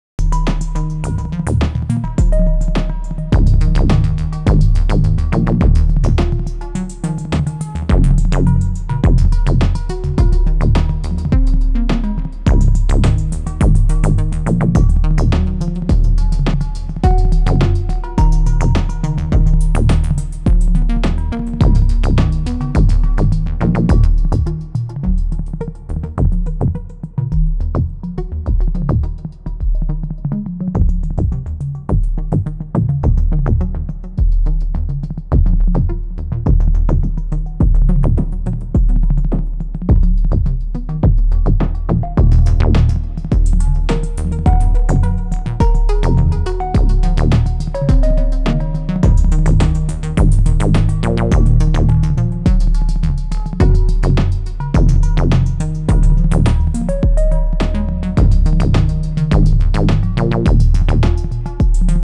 Bass: Raw
Leads: Chip + cowbell
HH: OH classic
BD: sample
Nice little groove and great snare!
The snare is the new UT Noise machine